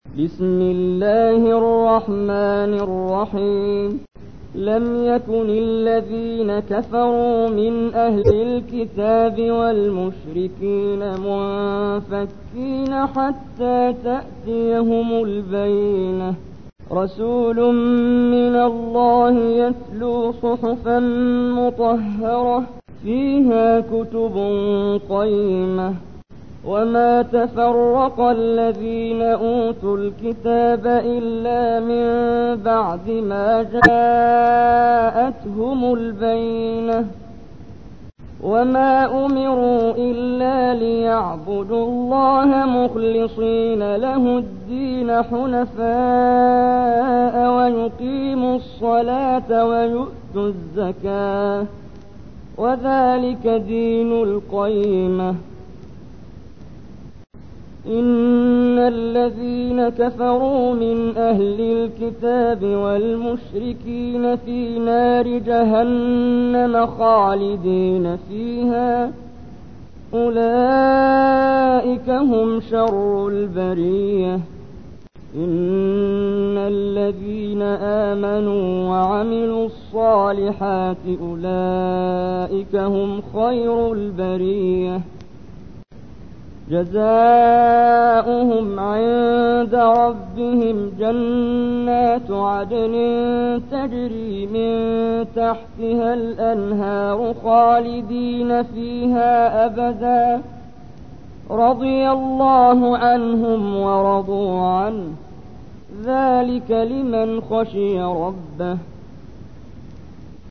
تحميل : 98. سورة البينة / القارئ محمد جبريل / القرآن الكريم / موقع يا حسين